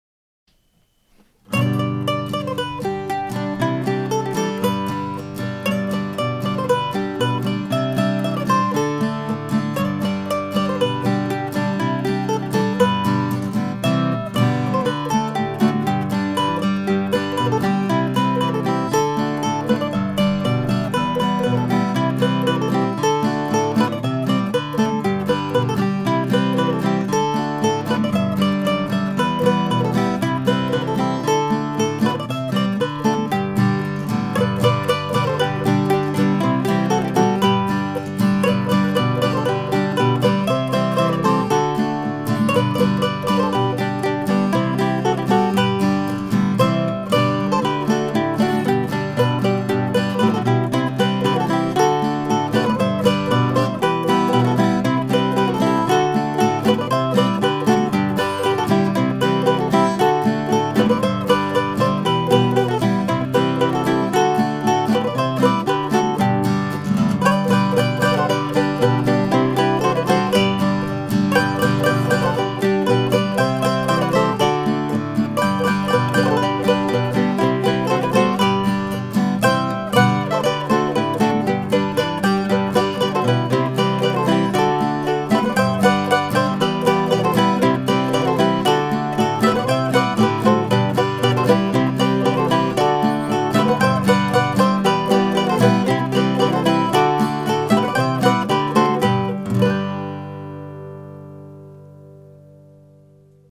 Contratopia has played this tune a couple of times now and it works as a nice mid-tempo marching kind of dance.